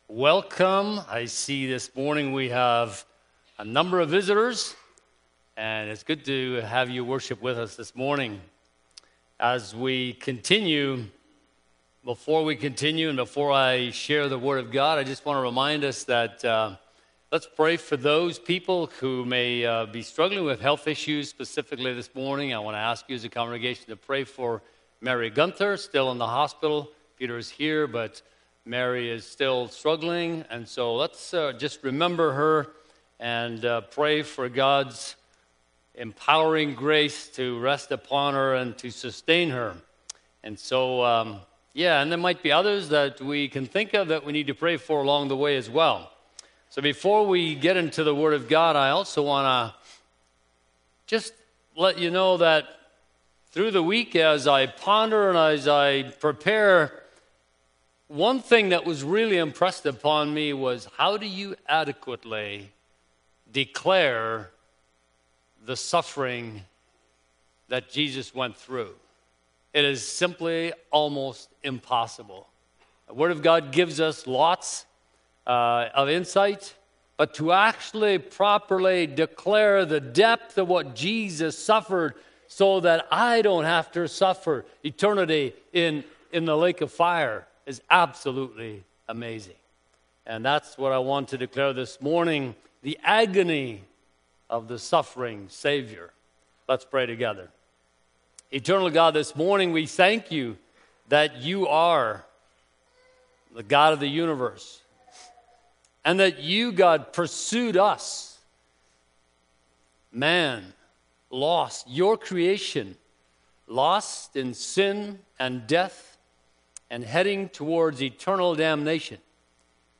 Good Friday Service